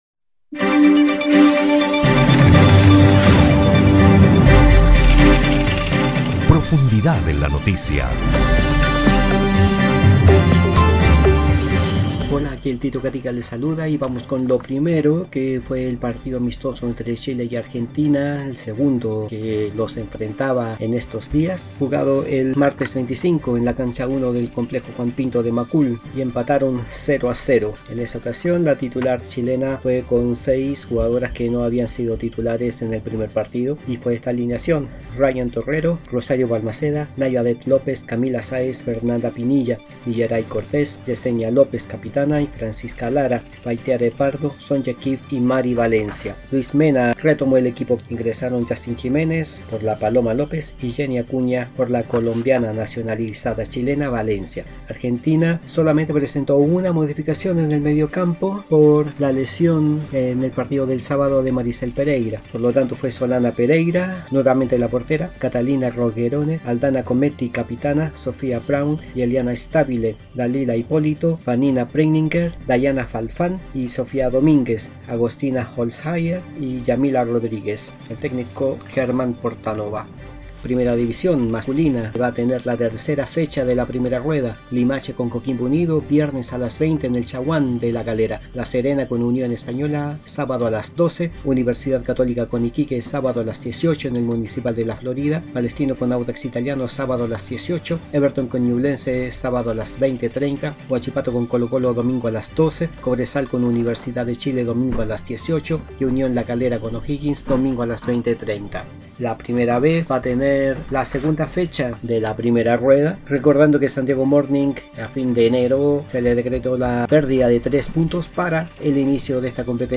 [Podcast / Informe] Resultados, programaciones y + del fútbol masculino y femenino